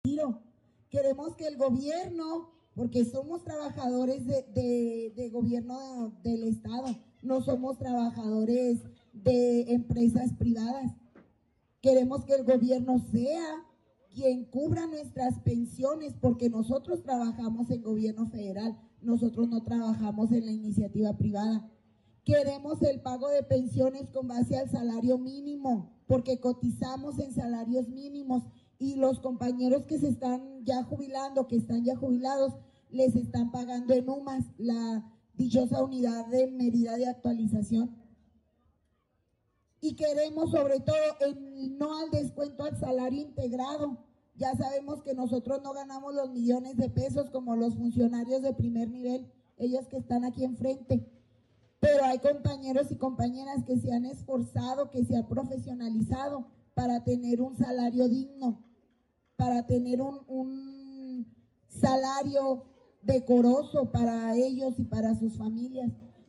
AUDIO-MAESTRA-REFORMA.mp3